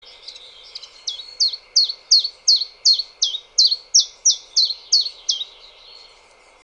Sekalaulava tiltaltti / A song switching Common Chiffchaff (Phylloscopus collybita)
normaalia tiltaltin laulua.
The bird also sang normal Chiffchaff song, but more seldom.